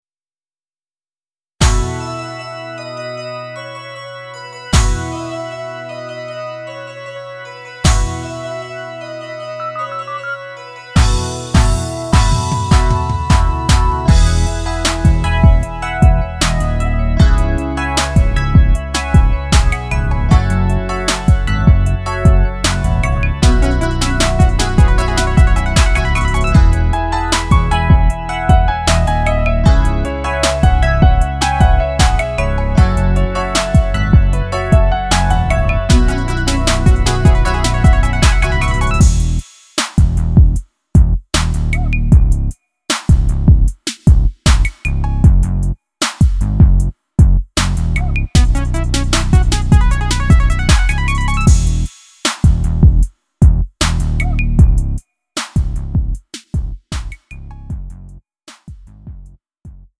음정 원키 3:25
장르 구분 Lite MR